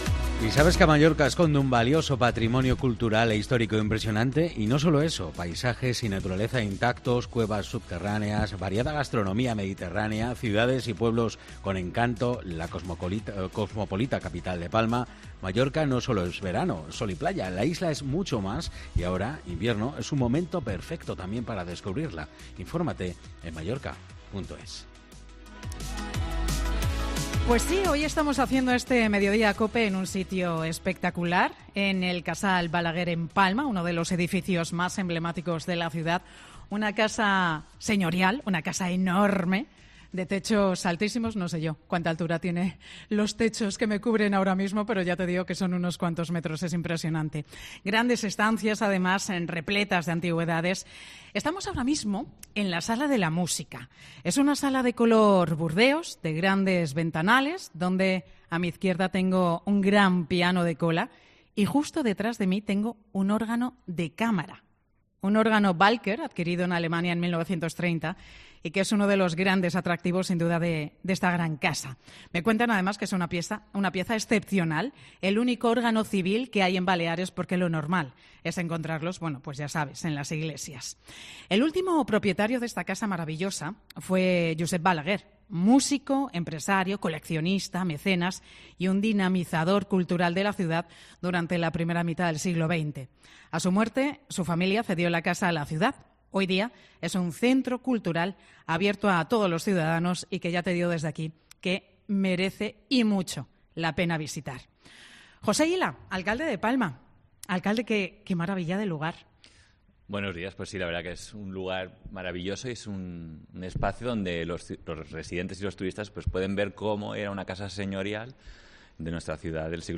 Entrevista en 'Mediodía en COPE' al alcalde de Palma, José Hila, sobre las preciosidades de la isla